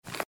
slide_btn.mp3